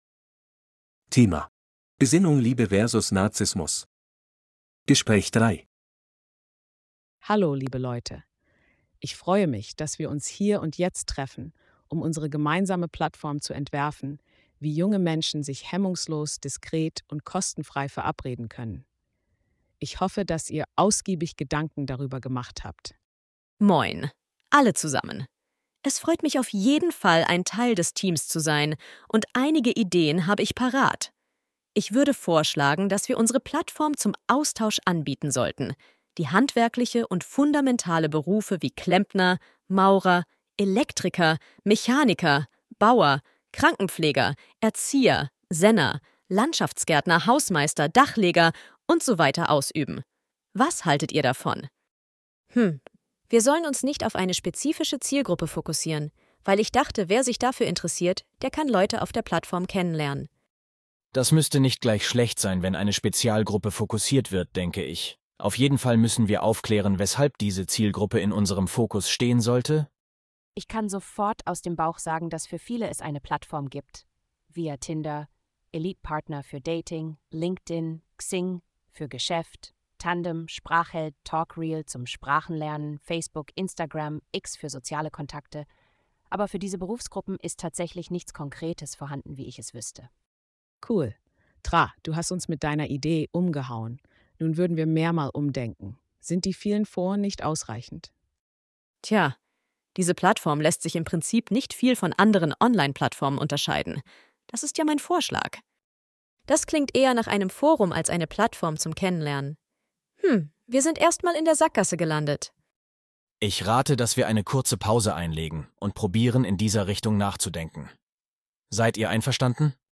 Bài nghe hội thoại 3:
B2-C1-Registeruebung-3-Besinnung-Liebe-vs.-Narzissmus-Gespraech-3.mp3